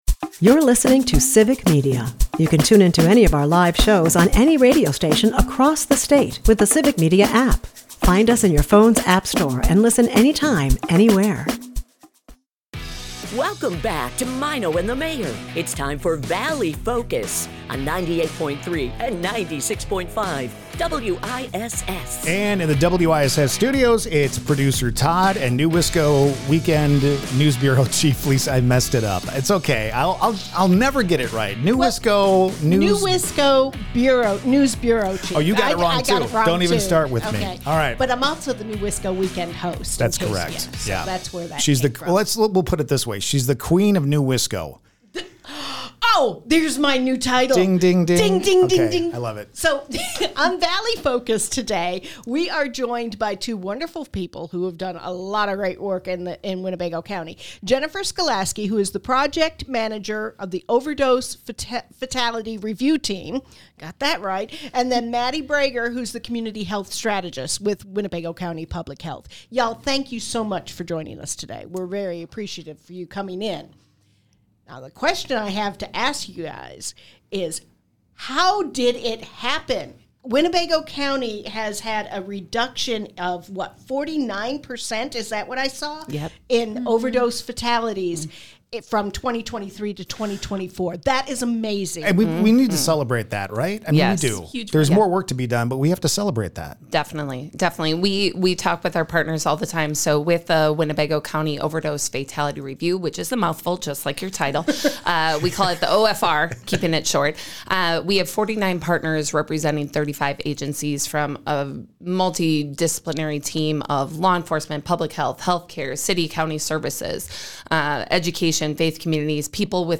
Valley Focus is a part of the Civic Media radio network and airs weekday mornings at 6:50 a.m. as part of the Maino and the Mayor Show on 96.5 and 98.3 WISS in Appleton and Oshkosh.